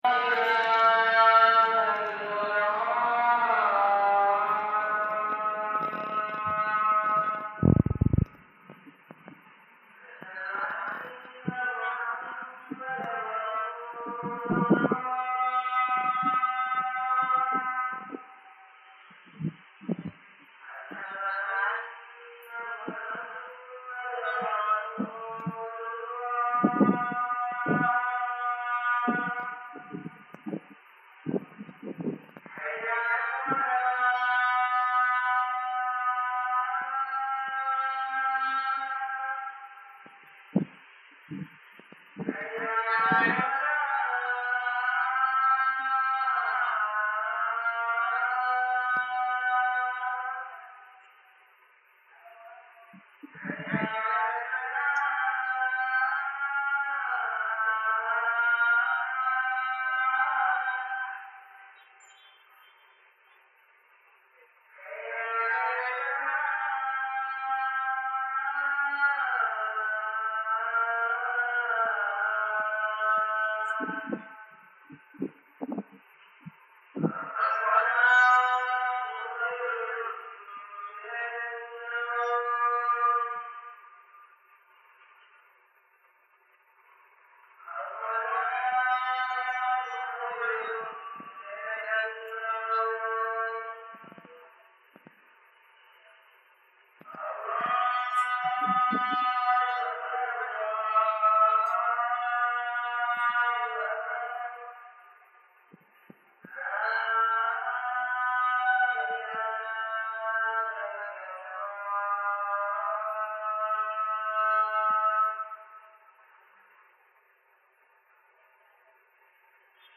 Upp ur sängen igen och fram med telefonen för att spela in, nästan på samma ställe som förra inspelningen (dvs ute på balkongen vid sovrummet). Även denna inspelning har filtrerats med samma filter som den förra, fast det behövdes inte lika mycket den här gången. Det verkar tyvärr som jag hållit för ena mikrofonen, så det är nästan bara ljud i höger stereokanal, men tänk er att det är i stereo 😉